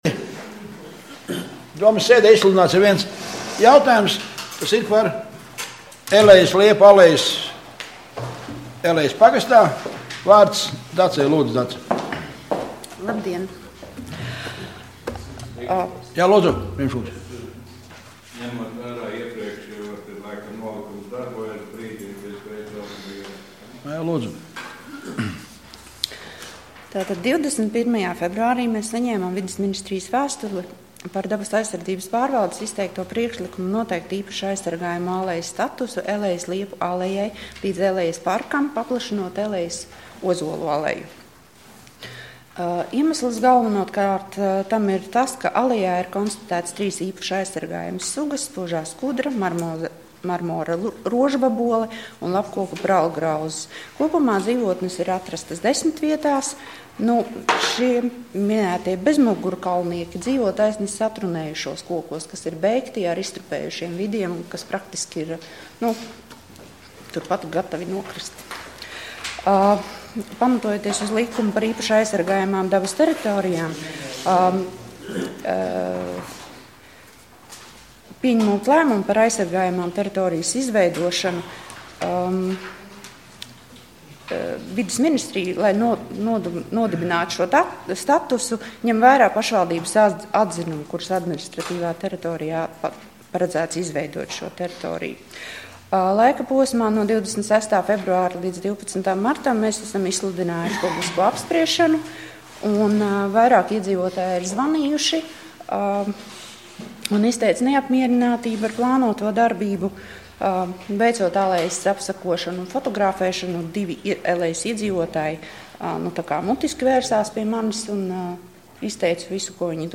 Domes ārkārtas sēde Nr. 3